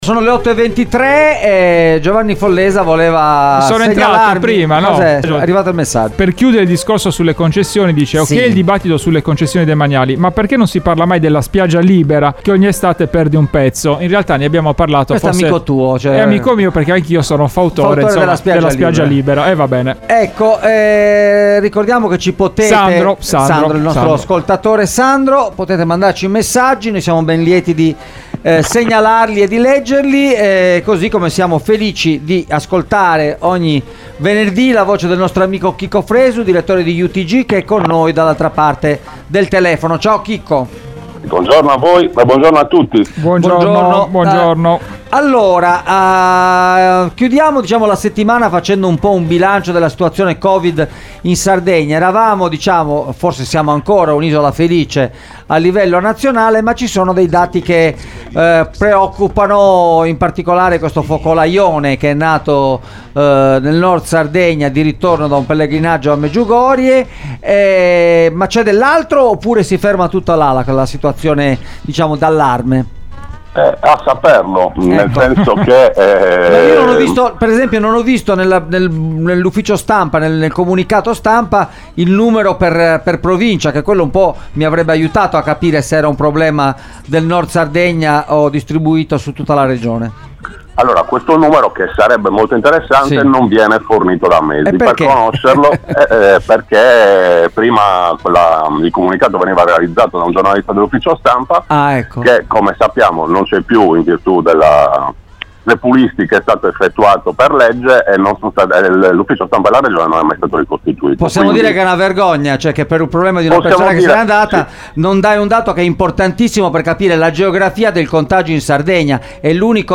Focolai nel nord Sardegna, la situazione del contagio nell’isola – Intervista